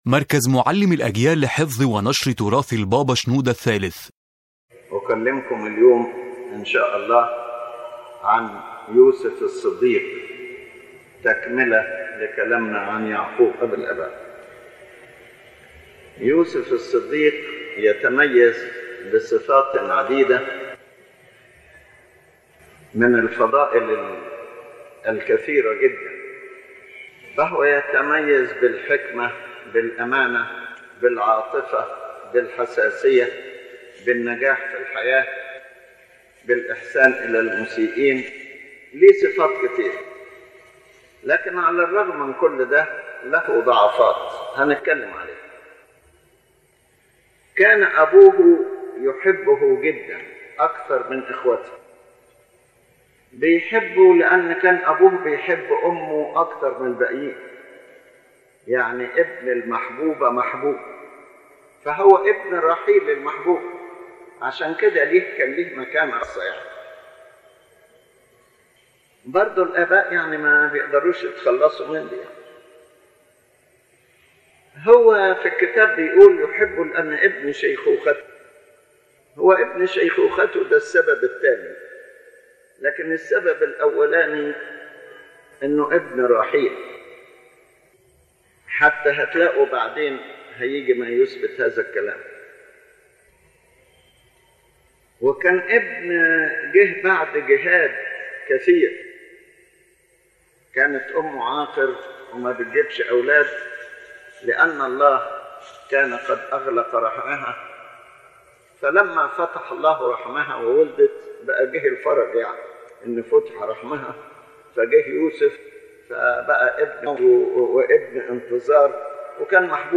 The lecture – spoken by His Holiness Pope Shenouda III – explains the personality of Joseph the Righteous, highlighting his virtues, his life struggles, how God accompanied him, and how the evil done against him was turned into blessing. It also addresses the mistakes of Joseph and his brothers, presenting deep spiritual lessons.